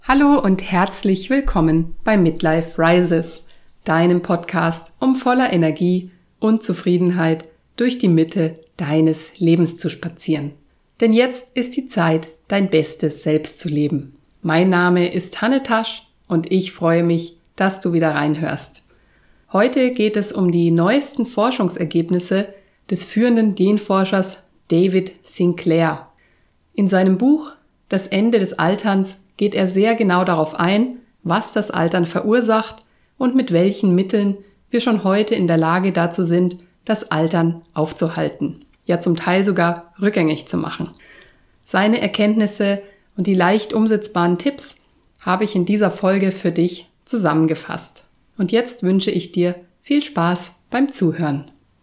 Alles was du brauchst sind Kopfhörer, damit es funktioniert. Am Anfang mag es etwas ungewohnt für deine Ohren klingen, aber langfristig wirst du den Effekt merken!